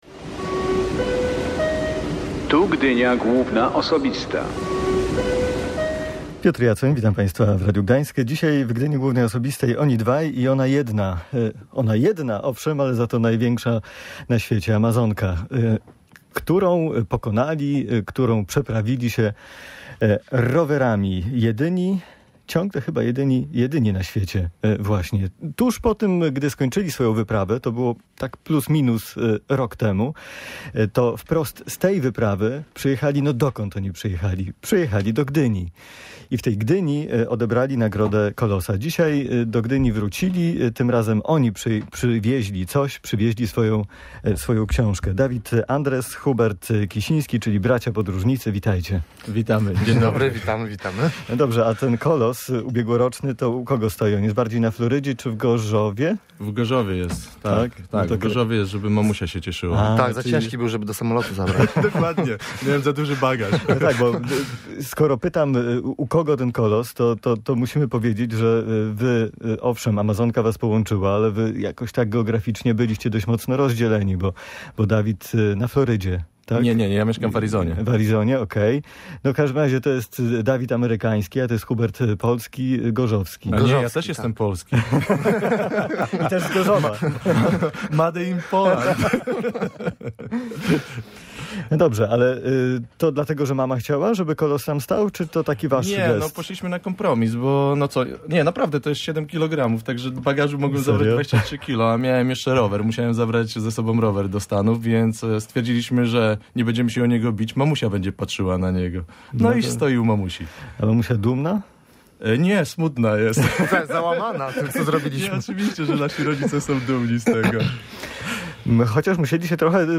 Gdynia Główna Osobista podróże rower